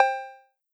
808 Bell.wav